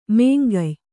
♪ mēngay